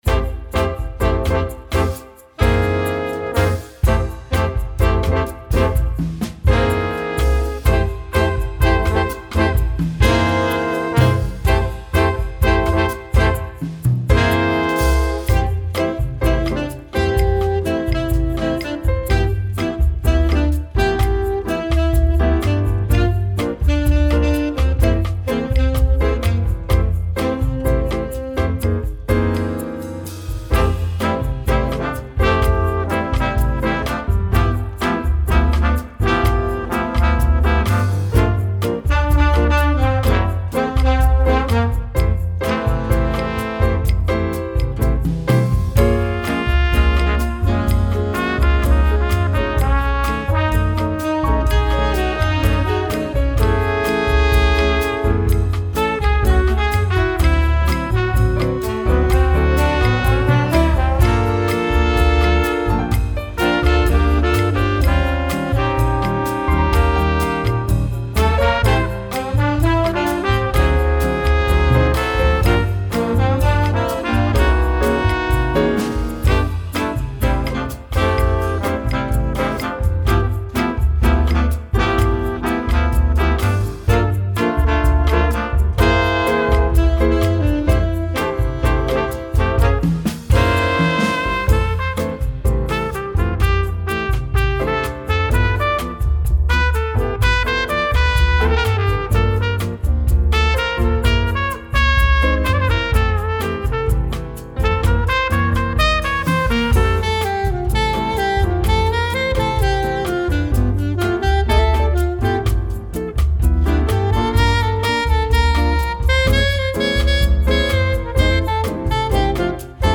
Voicing: Score and Parts